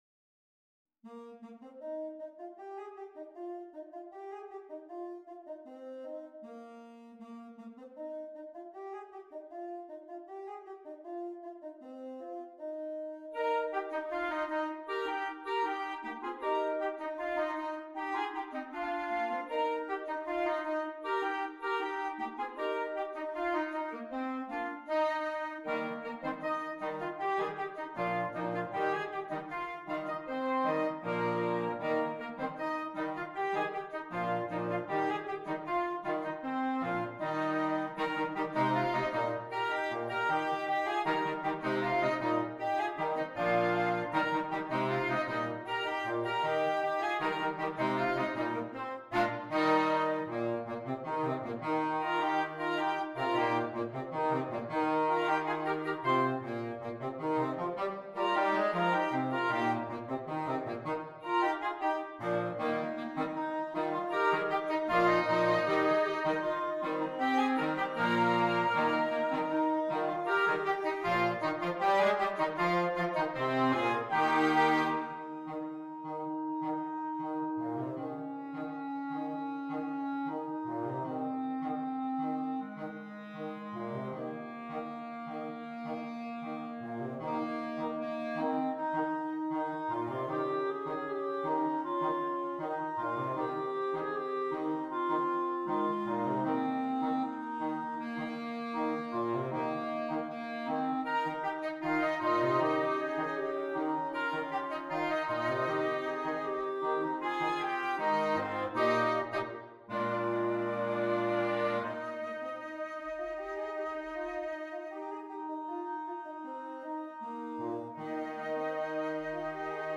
energetic, uplifting piece